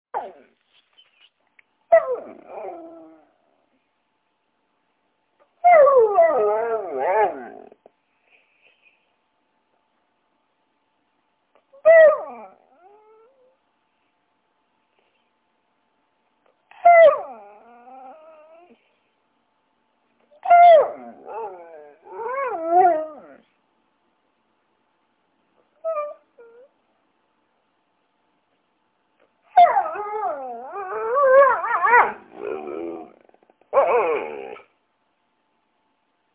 Dog Talking Or Howling Sound Button - Free Download & Play